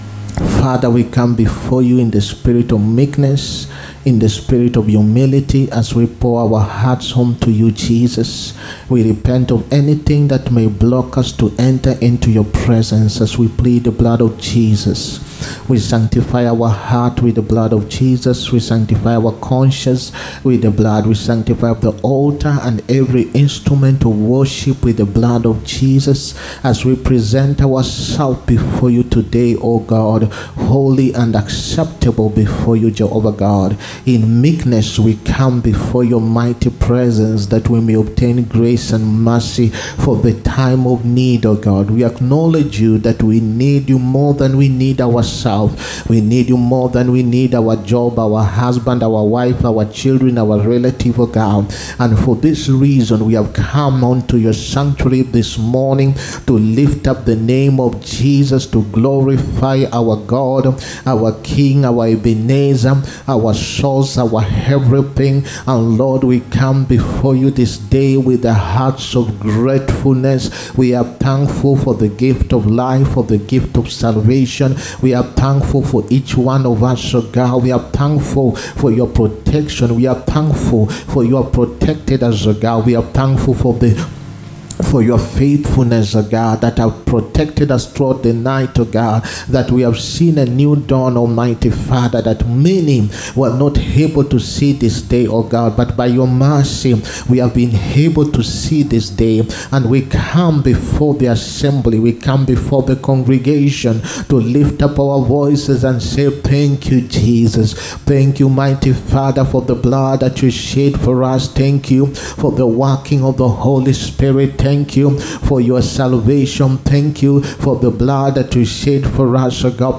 SUNDAY HOLY COMMUNION SERVICE. LIVING BY FAITH. 30TH MARCH 2025.